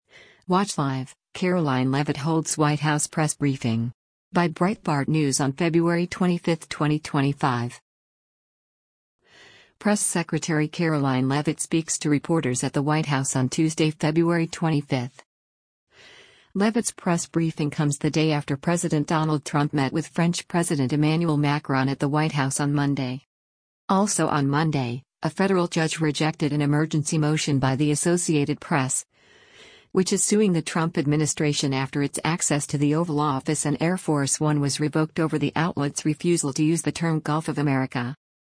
Press Secretary Karoline Leavitt speaks to reporters at the White House on Tuesday, February 25.